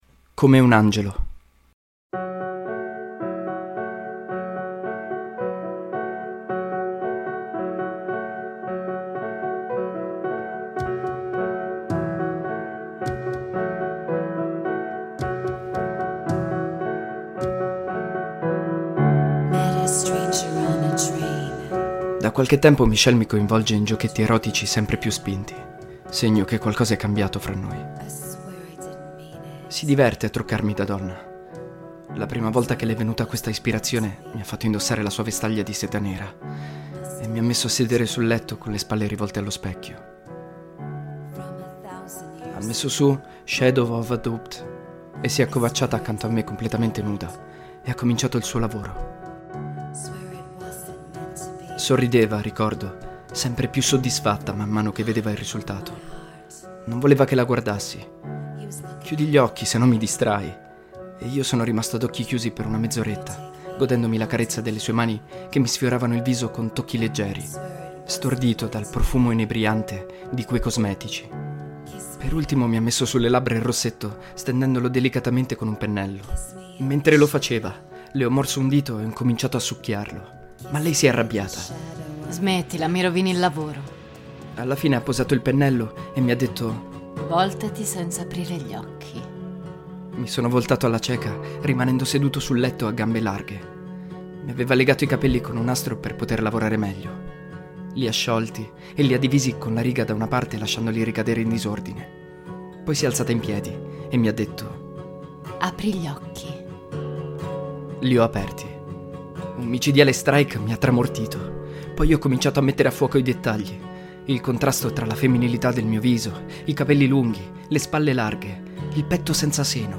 Nel corso dell'episodio si possono ascoltare cover dei seguenti brani: "Shadow of a doubt" dei Sonic Youth (cover di Black Tape For A Blue Girl), "Michelle" dei Beatles e "Between the bars" di Elliott Smith.